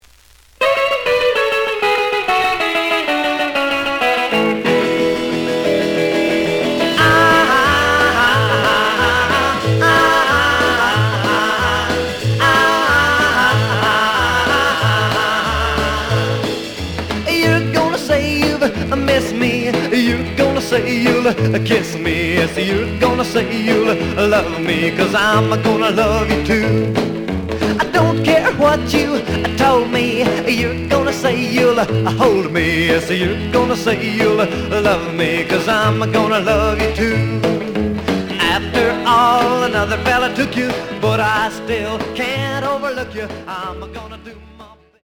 The audio sample is recorded from the actual item.
●Genre: Rhythm And Blues / Rock 'n' Roll
Some noise on beginning of both sides due to heat damage.